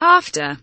after kelimesinin anlamı, resimli anlatımı ve sesli okunuşu